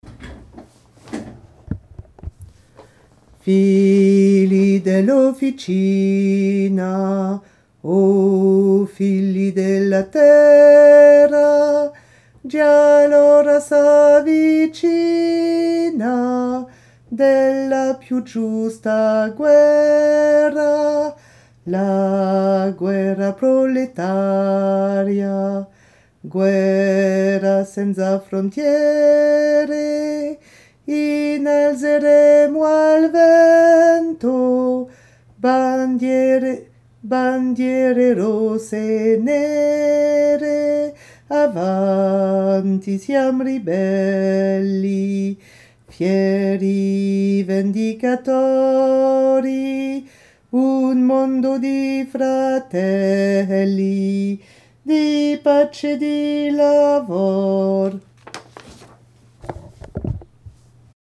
Figli basse
figli-basse.mp3